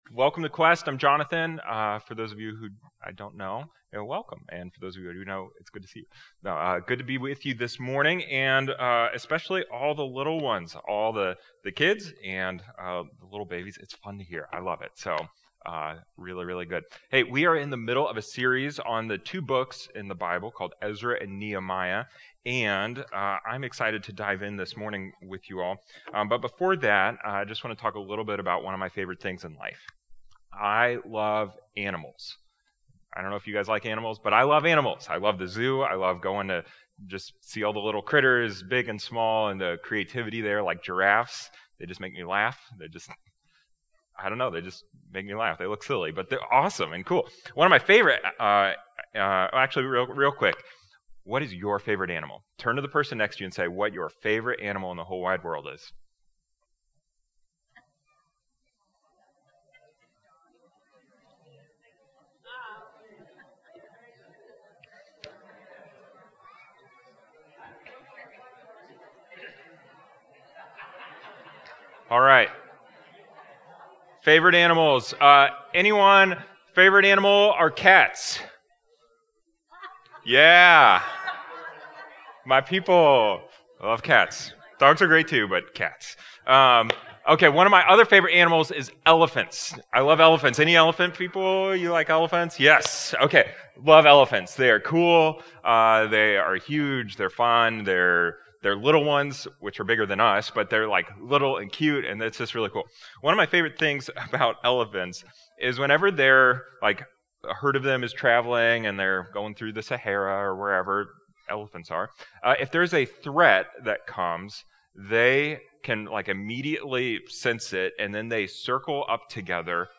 Also, we know that the sound on these livestreams isn't the greatest, but we're working on it.